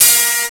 Index of /m8-backup/M8/Samples/Fairlight CMI/IIX/GUITARS